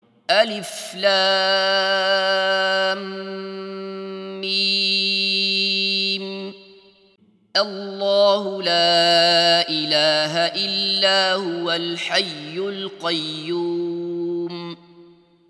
Alternativ 1: Stannar vid slutet av versen: Det är bäst att läsaren stannar vid slutet av den första versen och sedan går vidare till den andra versen.